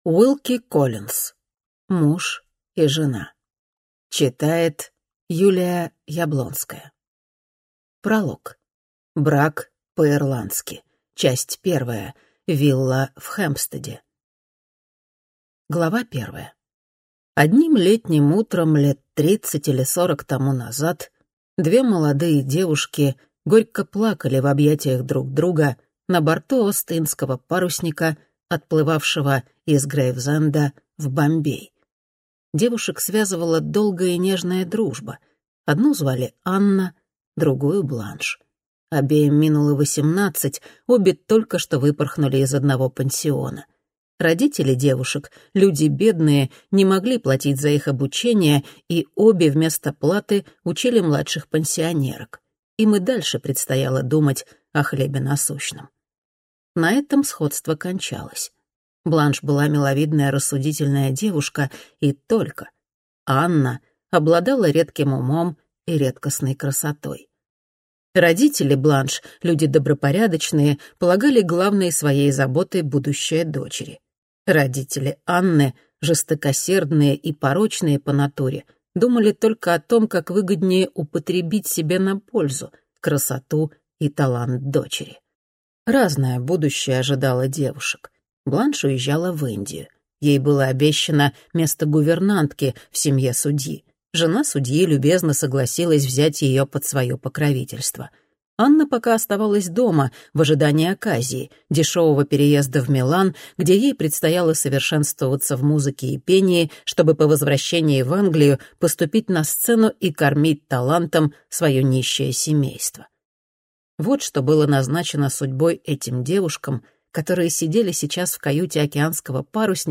Аудиокнига Муж и жена | Библиотека аудиокниг